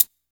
Closed Hats
Hat (16).wav